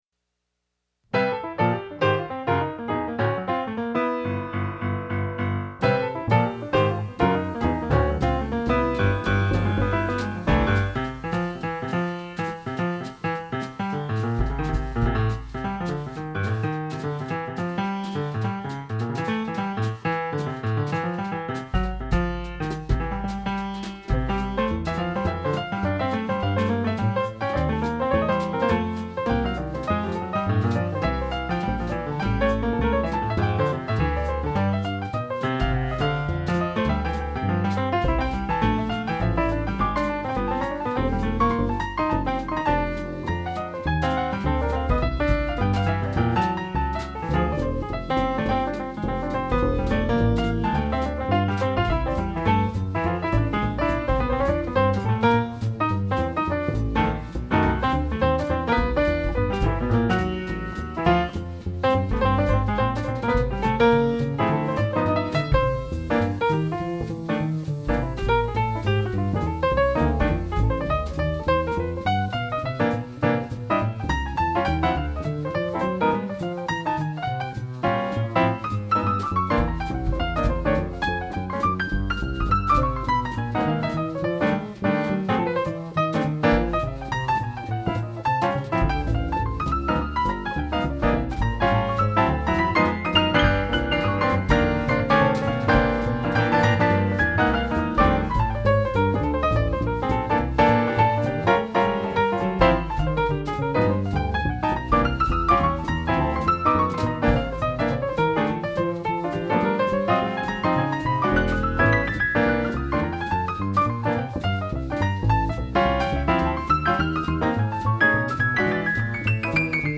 piano trio arrangement